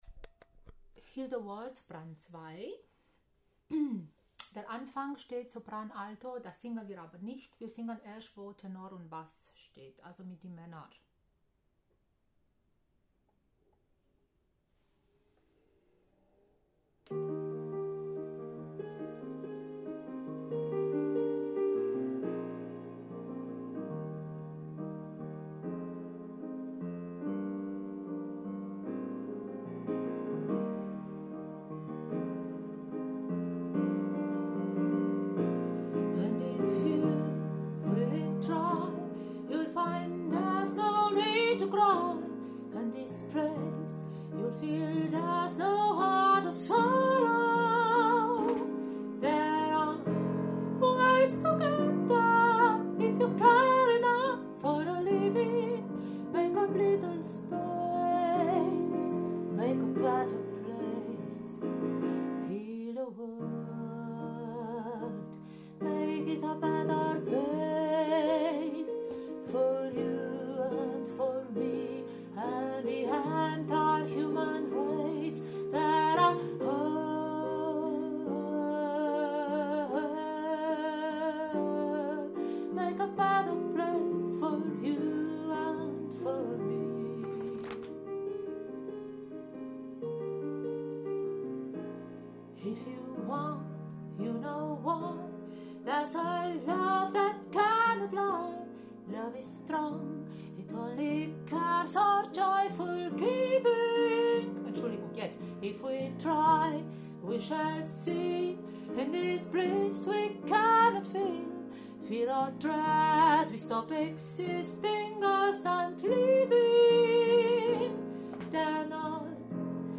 Heal-the-World-Alto2.mp3